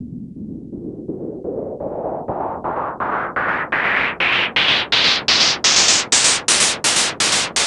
cch_fx_loop_blocks_125.wav